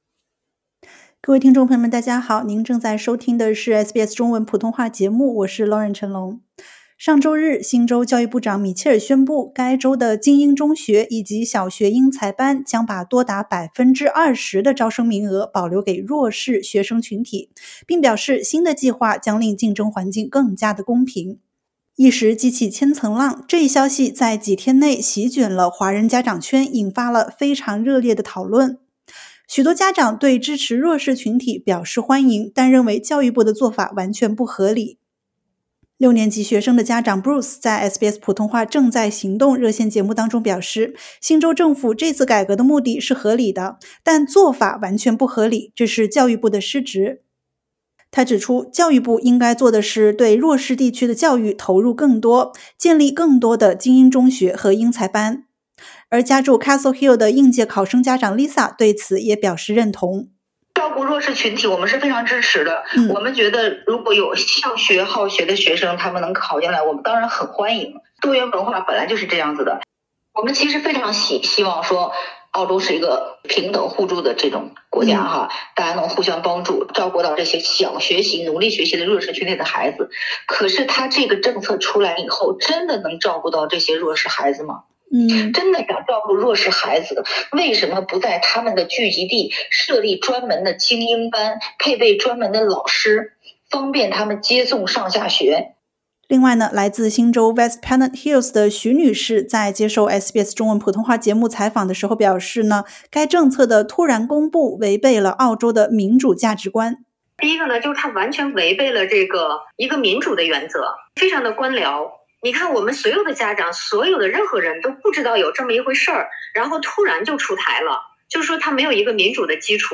新州精英公校将二成名额分配给弱势学生群体的新政在上周日公布后，几天内席卷了华人家长圈，引发了激烈讨论，有华人家长感觉“被针对了”。（点击上图收听采访）